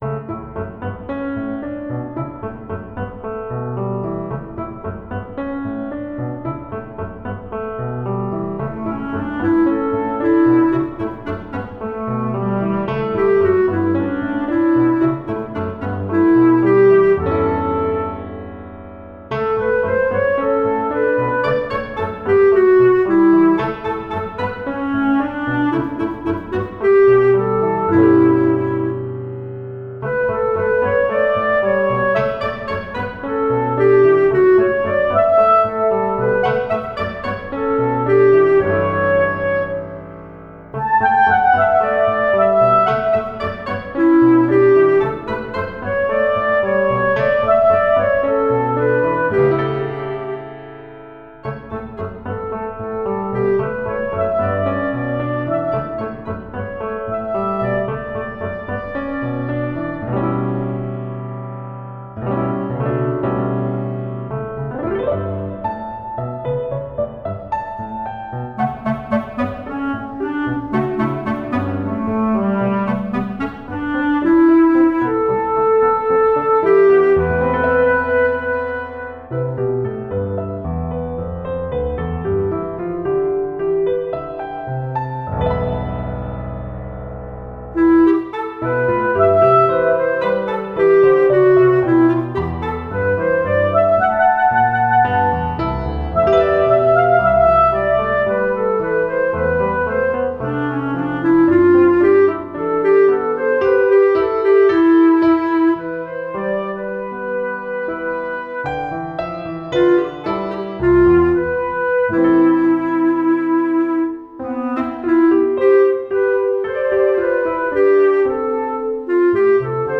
Chansonnettes pour Clarinette